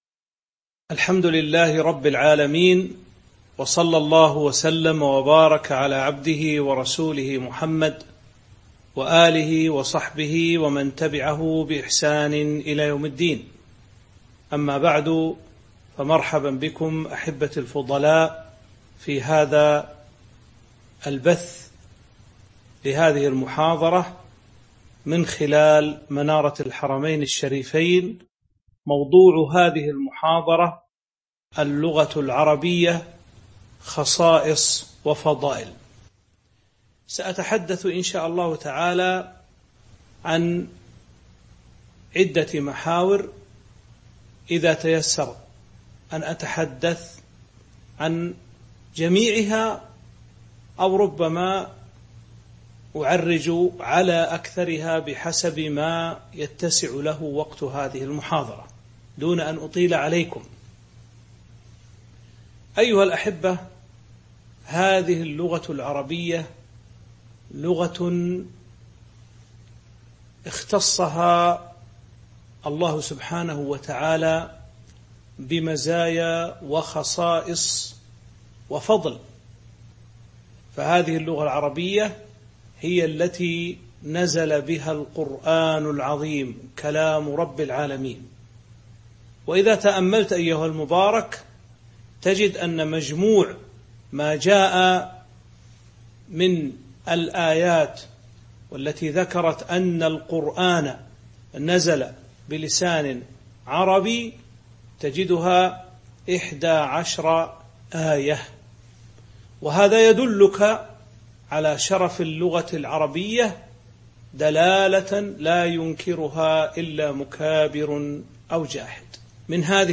تاريخ النشر ٩ جمادى الأولى ١٤٤٢ هـ المكان: المسجد النبوي الشيخ